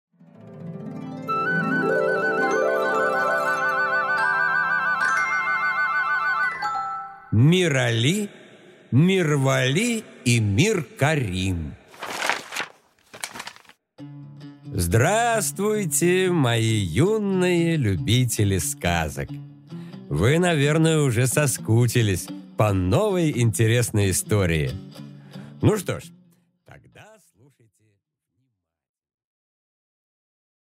Аудиокнига Мирали, Мирвали и Миркарим